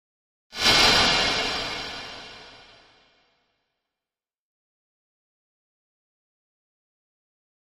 Spray High Frequency Metallic Spray with Reverb